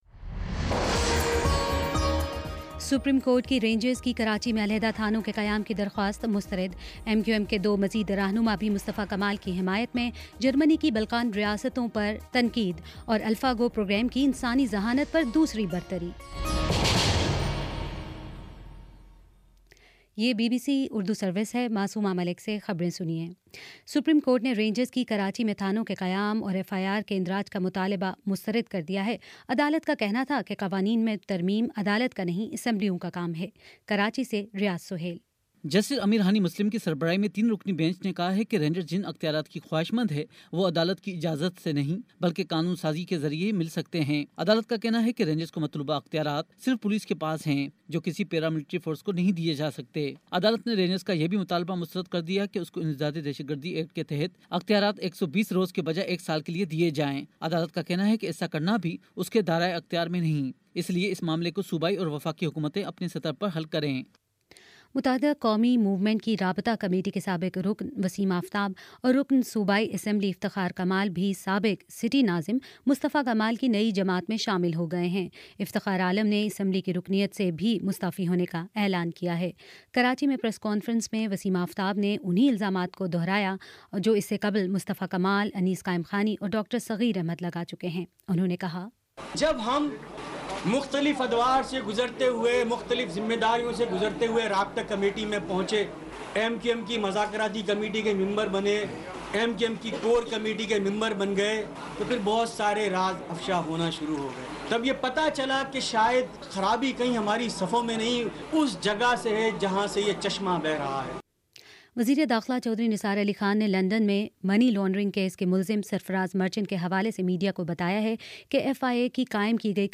مارچ 10 : شام سات بجے کا نیوز بُلیٹن